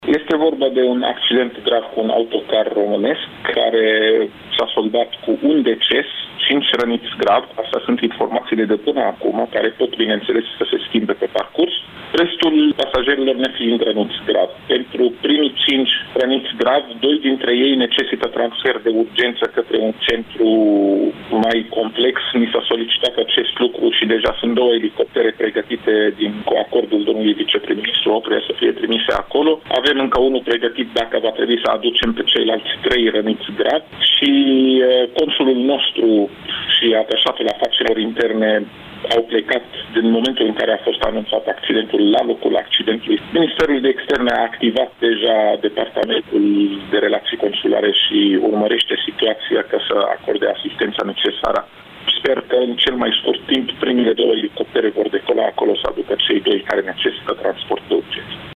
Secretarul de Stat în Ministerul Afacerilor Interne, Raed Arafat a declarat pentru Radio Iaşi că în prezent cazul este gestionat de Ministerul de Interne, departamentul pentru situaţii de urgenţă şi Ministerul de Externe.